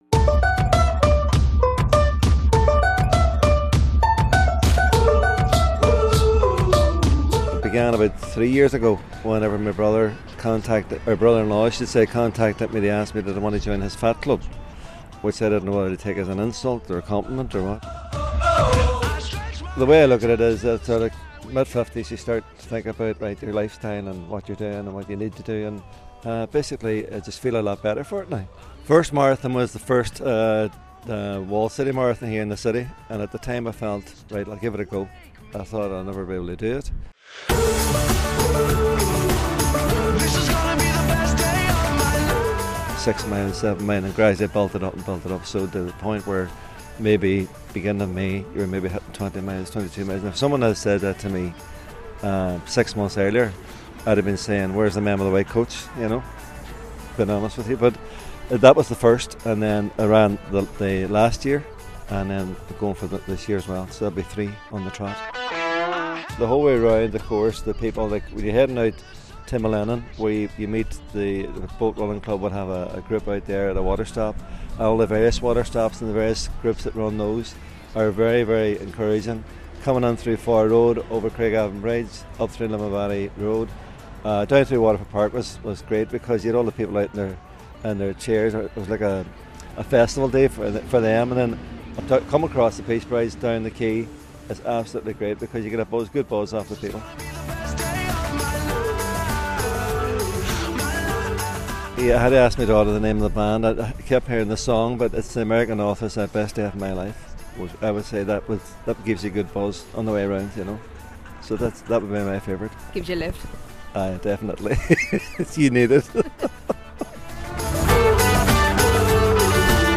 All this week on Breakfast we're looking ahead to next Sunday's Walled City Marathon and hearing from some of the runners, and their reasons for doing taking on the 26.2miles, as well as the music that motivates them to get through it.